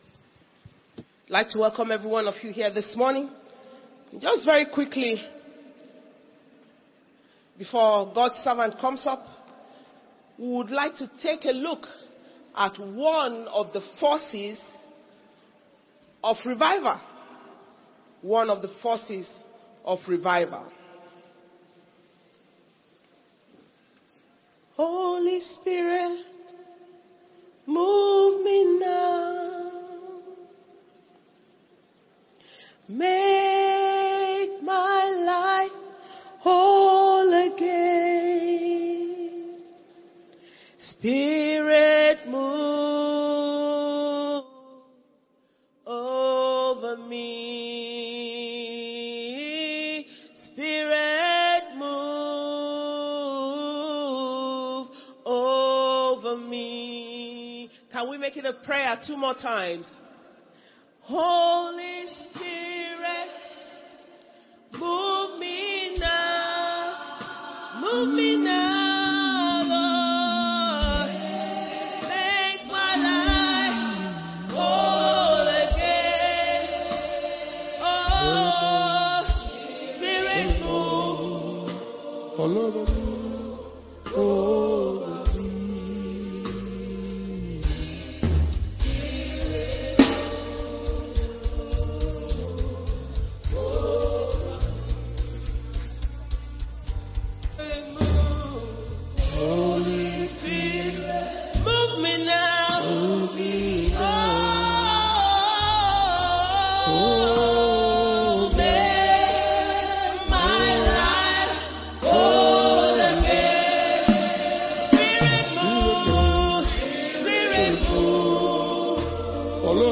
International Flaming Fire Conference 2023 - Day 2 Morning Session